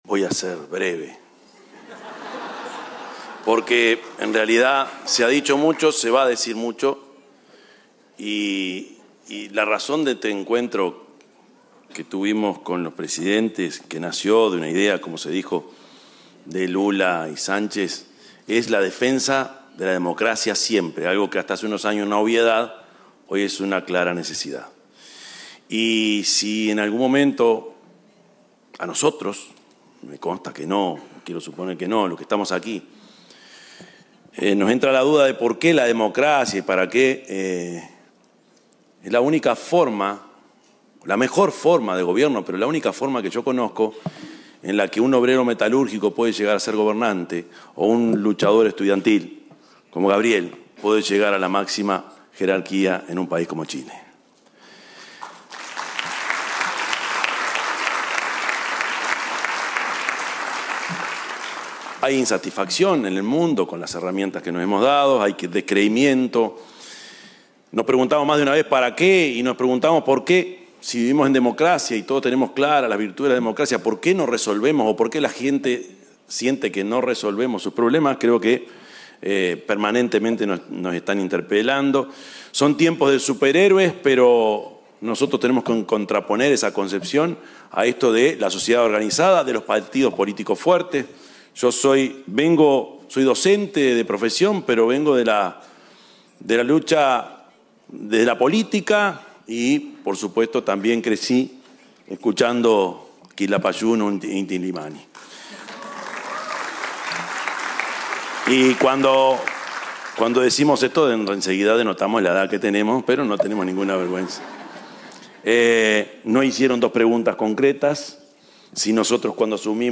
Palabras del presidente de la República, Yamandú Orsi
El presidente Yamandú Orsi disertó en un encuentro con representantes de organizaciones internacionales, centros de pensamiento, la academia y la
Palabras del presidente de la República, Yamandú Orsi 21/07/2025 Compartir Facebook X Copiar enlace WhatsApp LinkedIn El presidente Yamandú Orsi disertó en un encuentro con representantes de organizaciones internacionales, centros de pensamiento, la academia y la sociedad civil, en el marco de la reunión de alto nivel Democracia Siempre, en Chile.